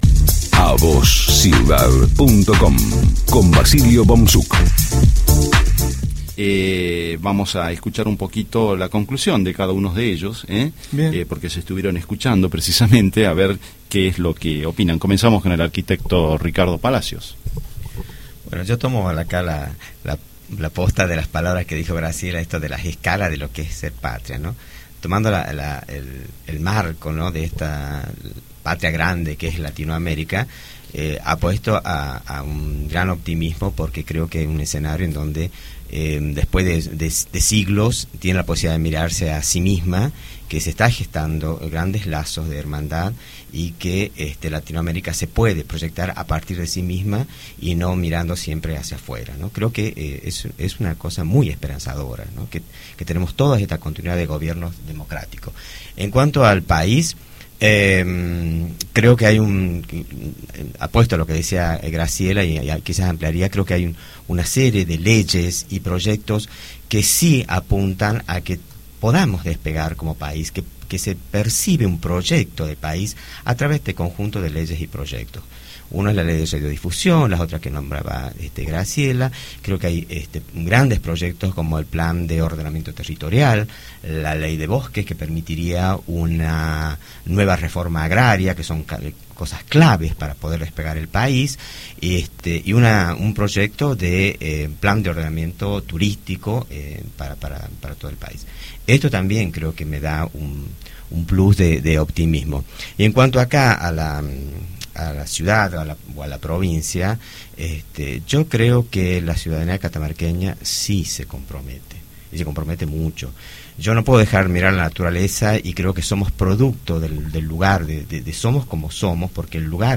El sábado 22 de mayo emitimos un programa especial para celebrar el Bicentenario de la Patria. Para la ocasión contamos con la presencia de invitados especiales.